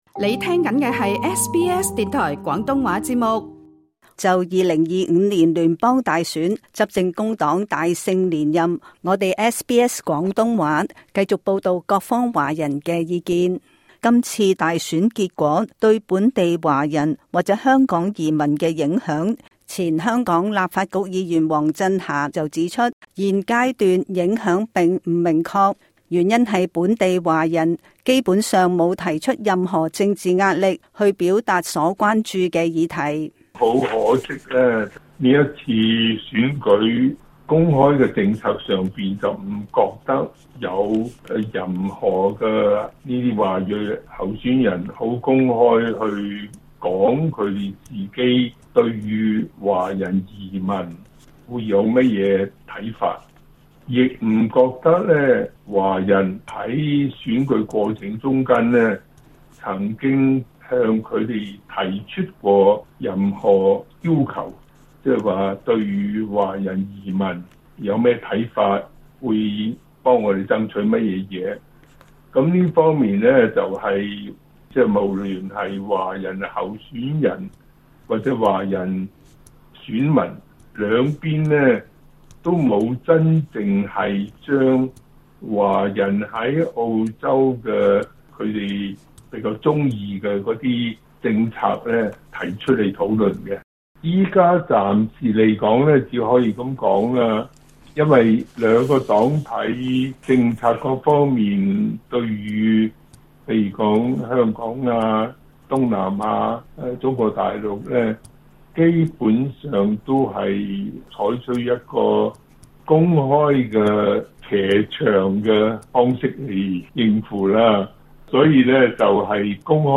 前立法局議員黃震遐 詳情請聽就今屆聯邦大選工黨連任，華人意見專題報道。